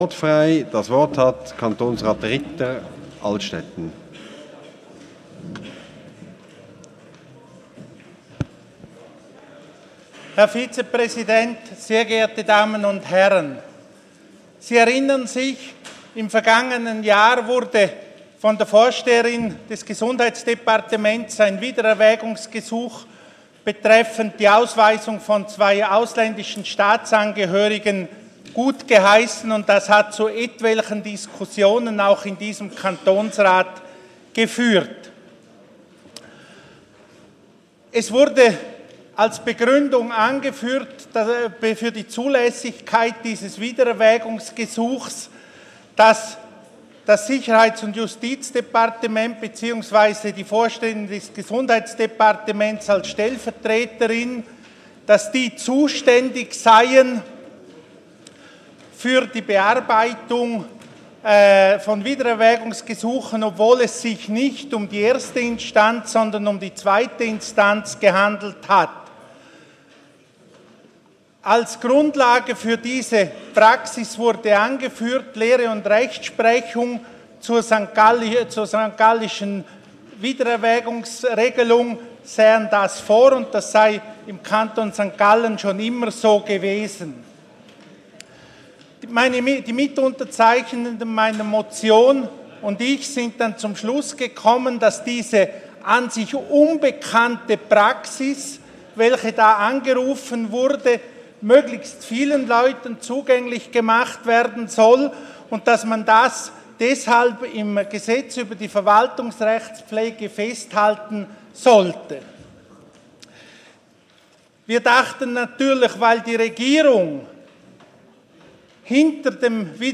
1.3.2016Wortmeldung
Session des Kantonsrates vom 29. Februar bis 2. März 2016, ausserordentliche Session vom 3. März 2016